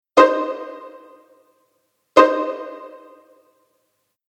| pizzicato effect |